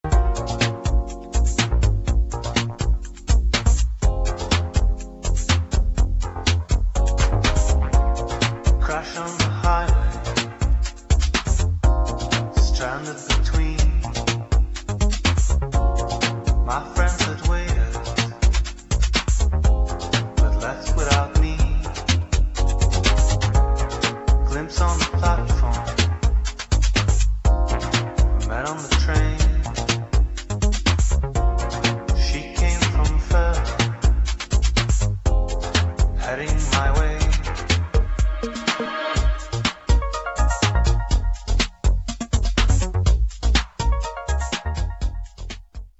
[ POP / HOUSE ]